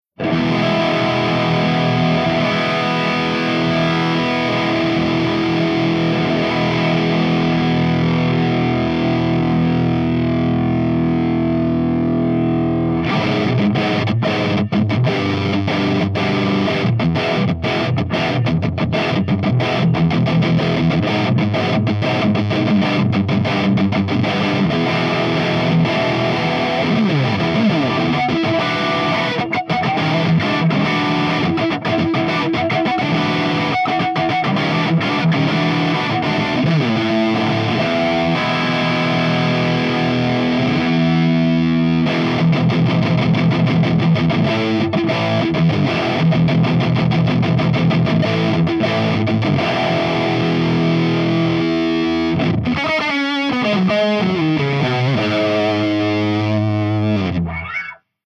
150_MESA SINGLE RECTIFIER_CH2HIGHGAIN_V30_SC